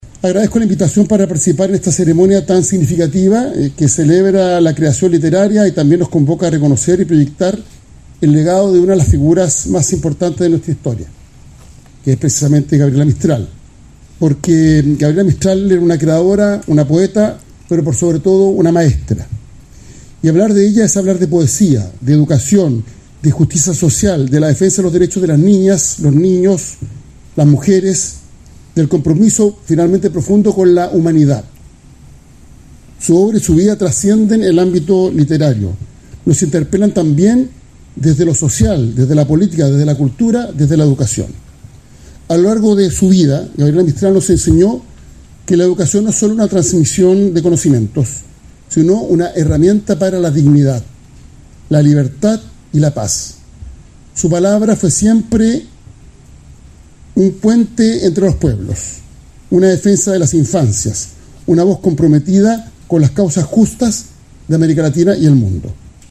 VICEPDTE-ELIZALDE-Alvaro-Elizalde-Vicepresidente-de-la-Republica.mp3